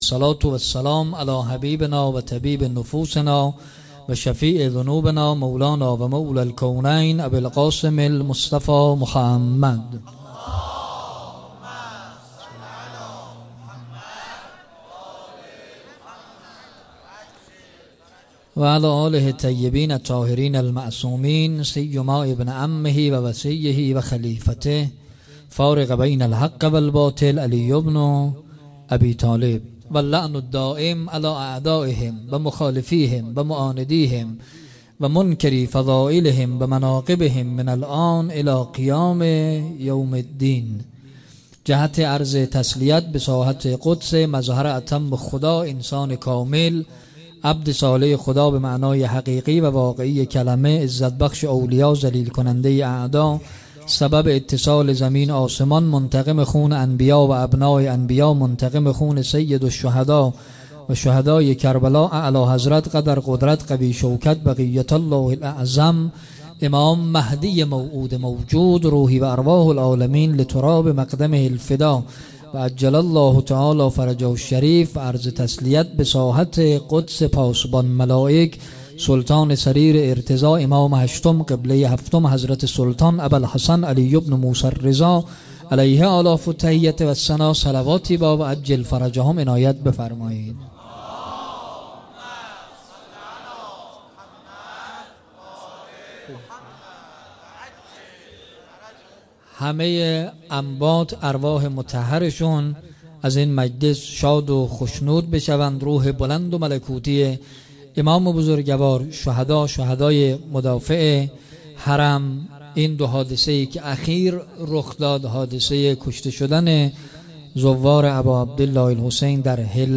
شب اول دهه سوم صفر 95 - هیات انصارالحجه - سخنرانی